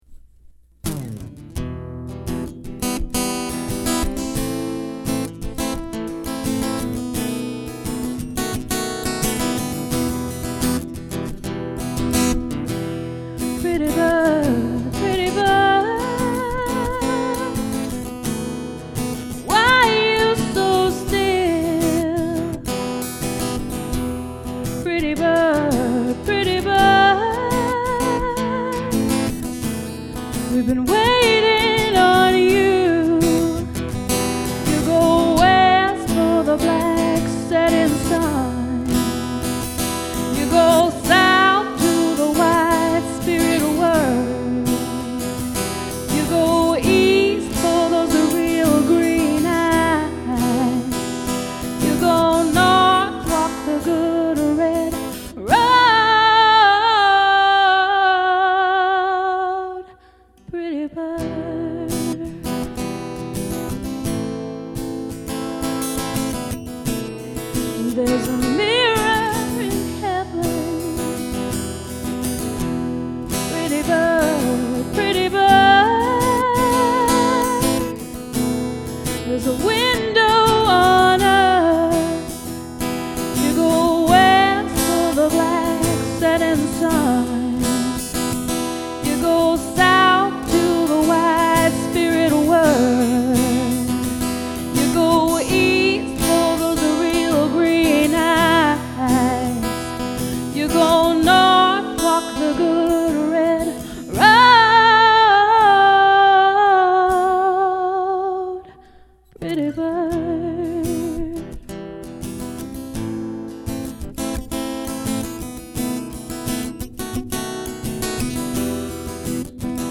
Vocals
Guitar